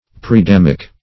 Preadamic \Pre`a*dam"ic\